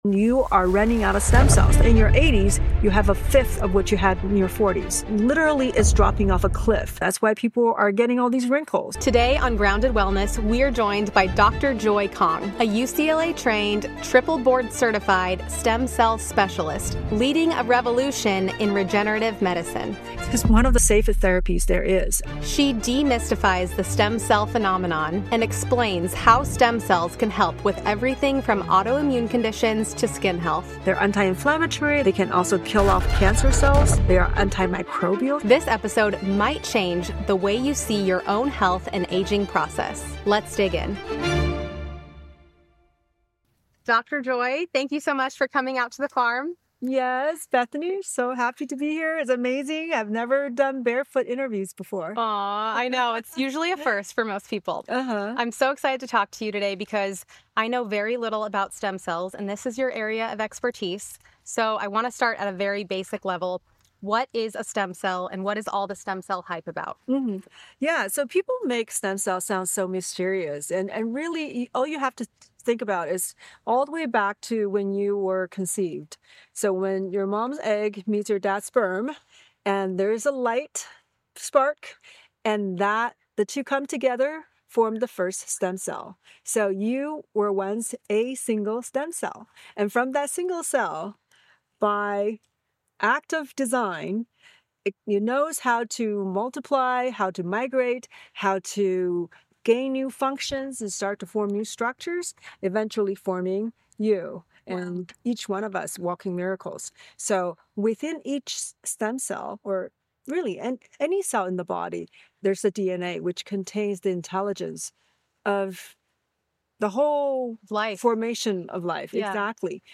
This conversation isn’t just about stem cells.